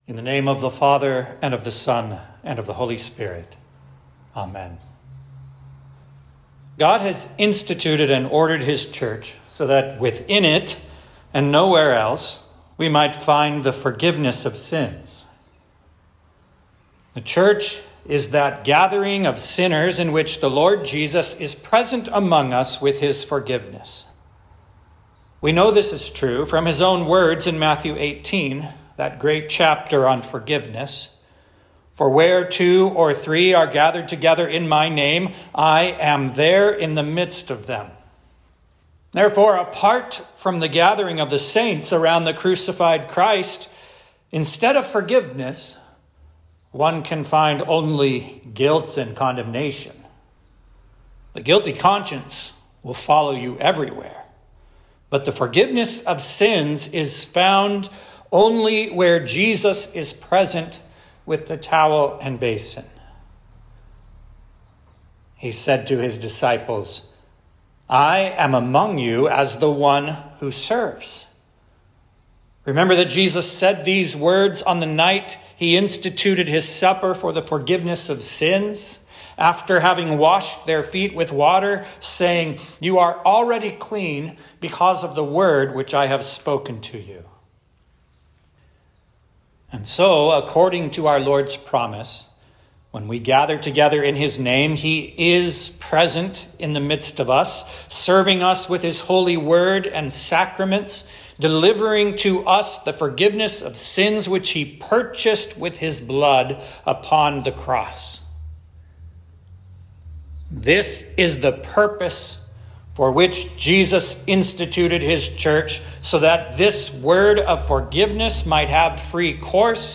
Religion